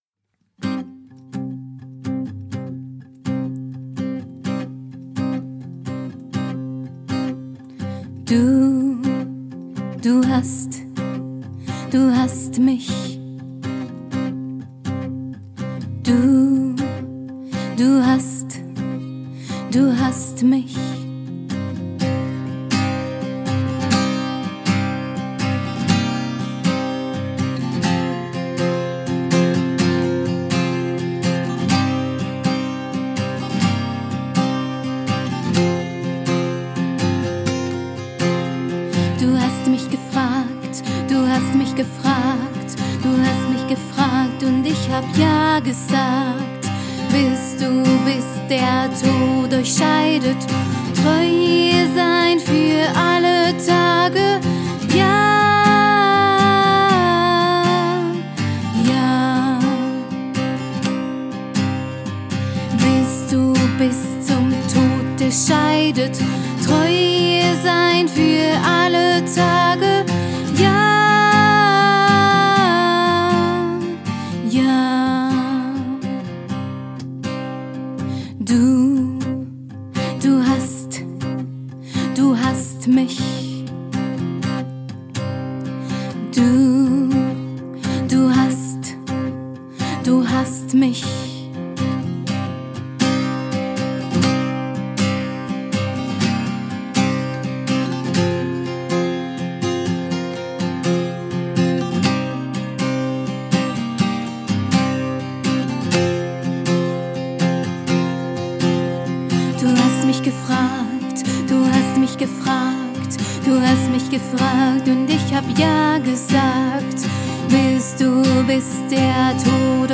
Hochzeits- und Eventsängerin